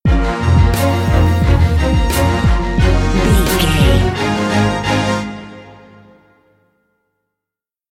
Fast paced
In-crescendo
Aeolian/Minor
B♭
strings
drums
horns